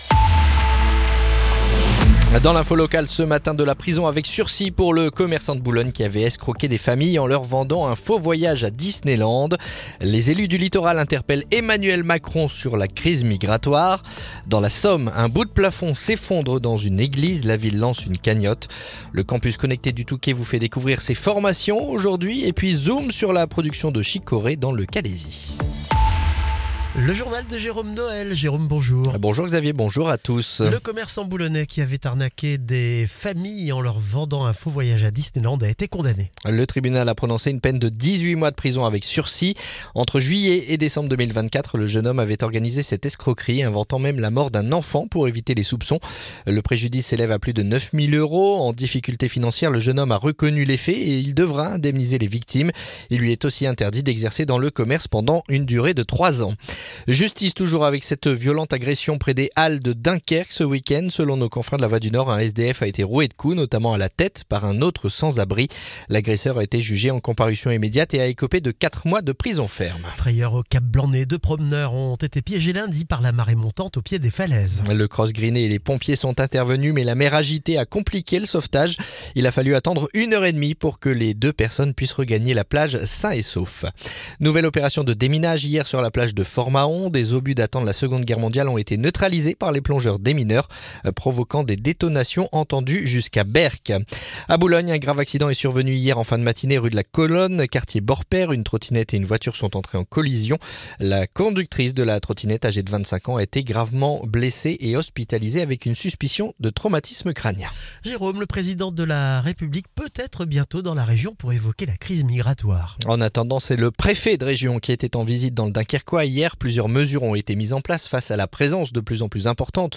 Le journal du mercredi 24 septembre